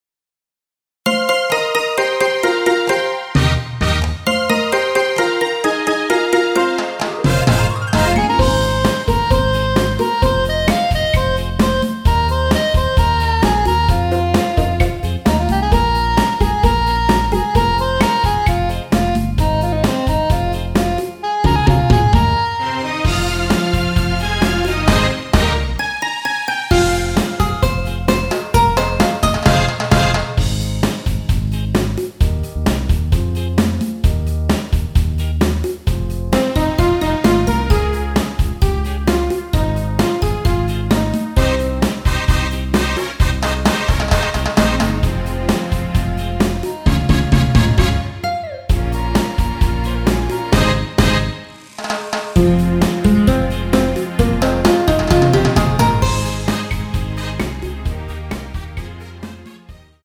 MR 입니다.
Fm
앞부분30초, 뒷부분30초씩 편집해서 올려 드리고 있습니다.
중간에 음이 끈어지고 다시 나오는 이유는